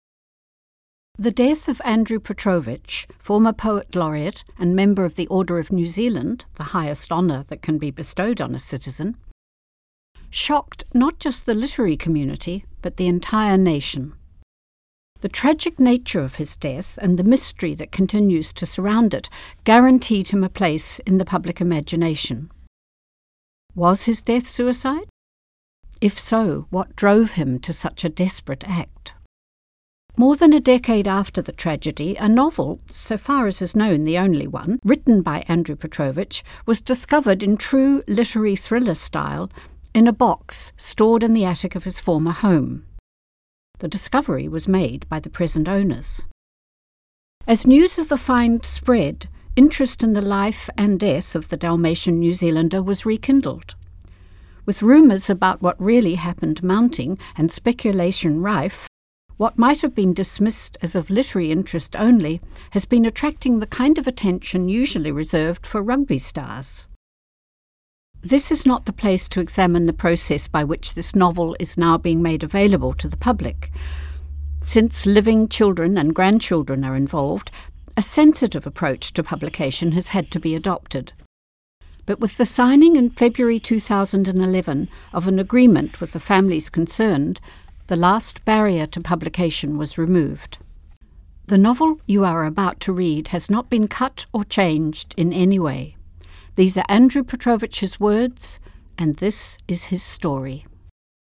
reading from Obsession